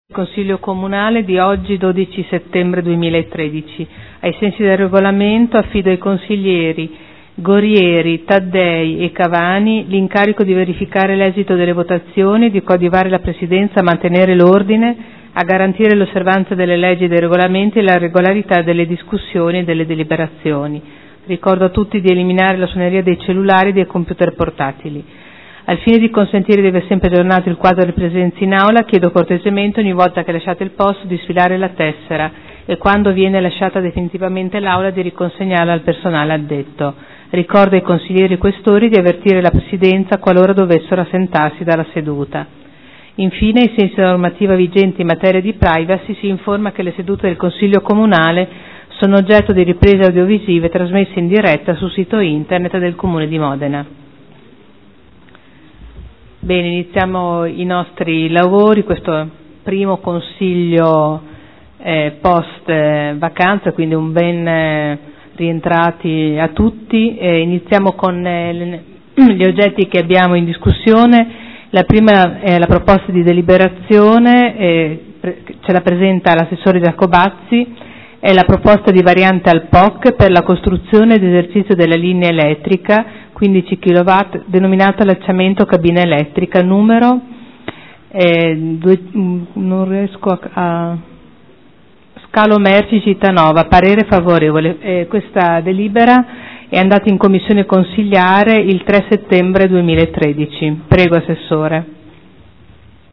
Il Presidente Caterina Liotti, dopo le interrogazioni, apre i lavori del Consiglio.